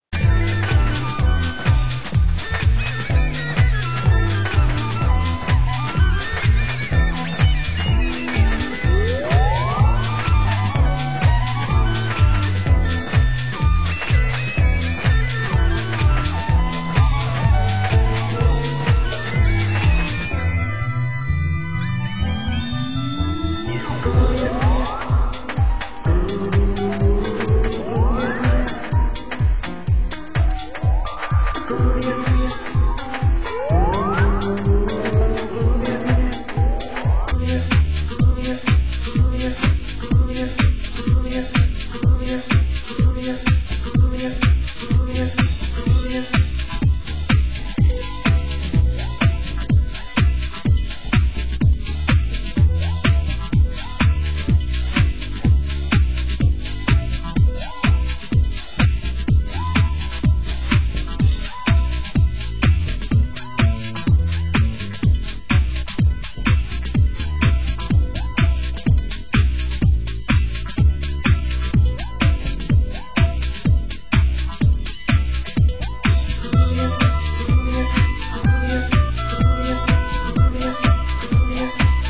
Genre: UK House
trip jazz mix, rudeuro mix